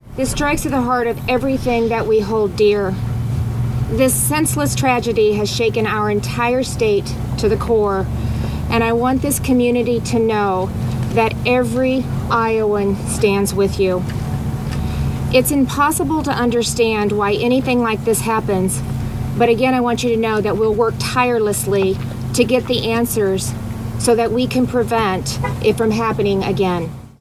Governor Kim Reynolds opened this (Thursday) afternoon’s news conference with a message to the Perry community.